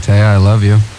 Radio show 1